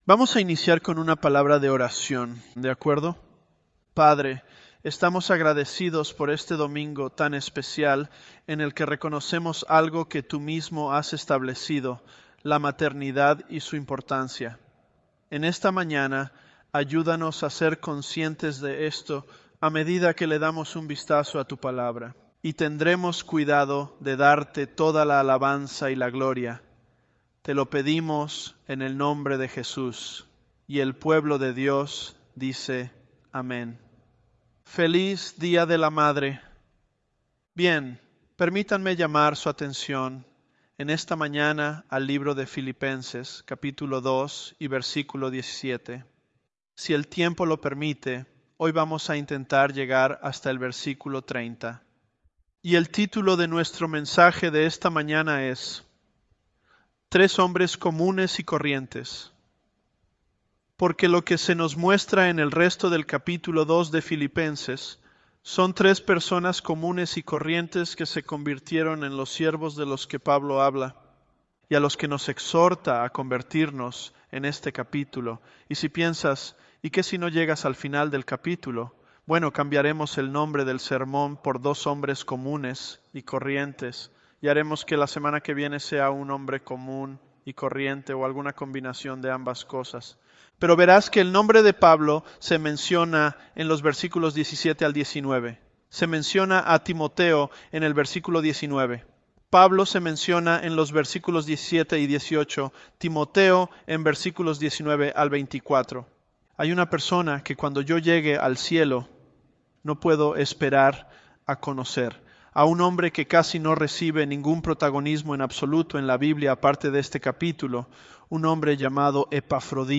Elevenlabs_Philippians006.mp3